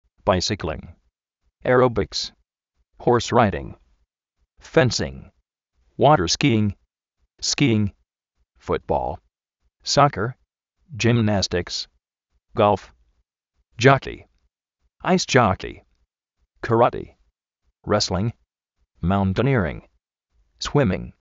báisikling
aeróbiks
jors ráiding
fénsing
uórer skí:ng
sóker
yimnástiks
suíming